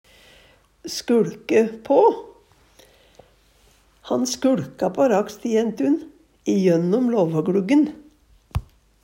DIALEKTORD PÅ NORMERT NORSK skuLke på titte på, glytte på Eksempel på bruk Han skuLka på rakstejentun jønnom låvågluggen.